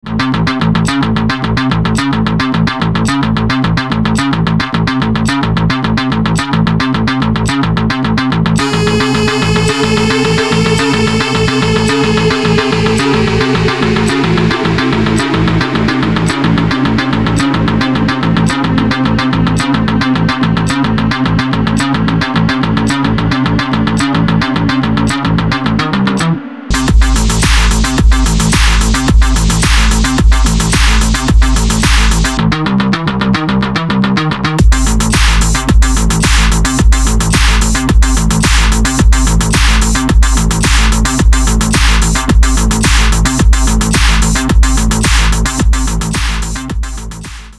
club
тревожные
Dubstep